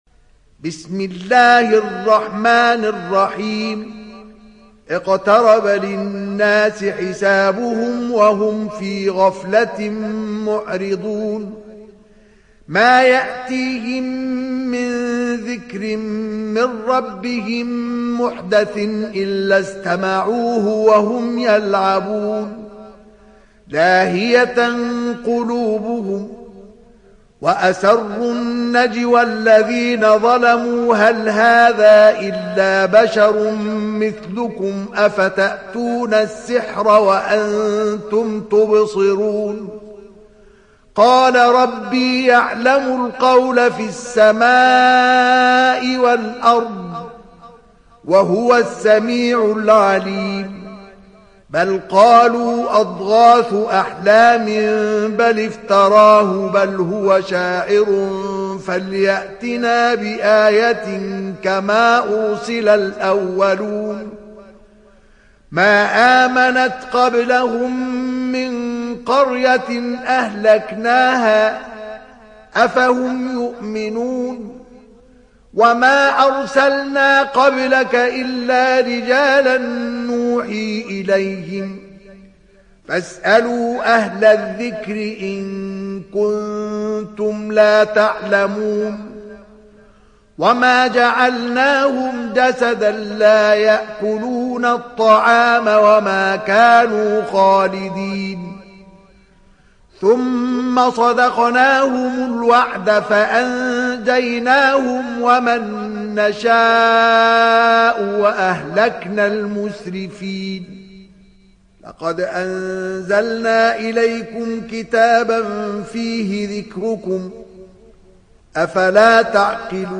دانلود سوره الأنبياء mp3 مصطفى إسماعيل (روایت حفص)